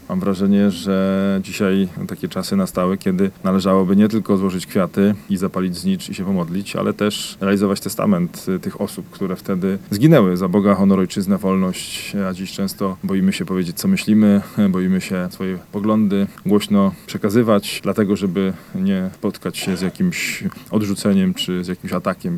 Mówi Piotr Chęciek starosta powiatu dębickiego.